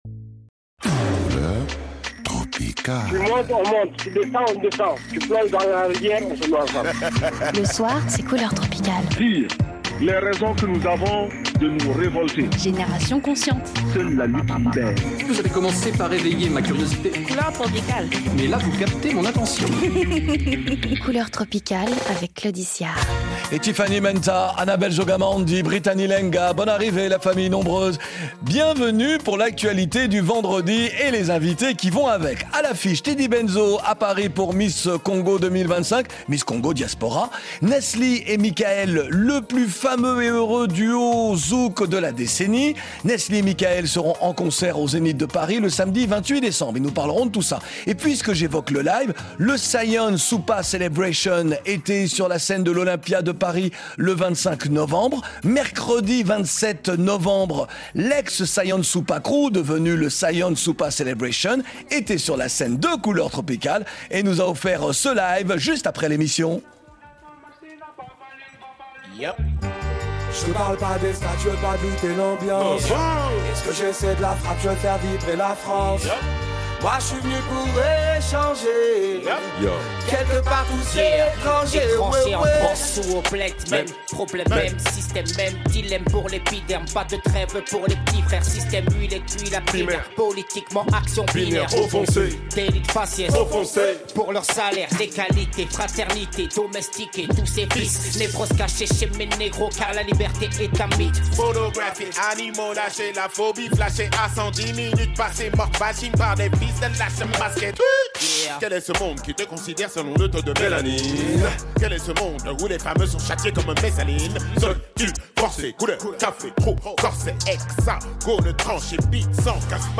Et le blind-test enregistré avec Saïan Supa Célébration, le 27 novembre 2024.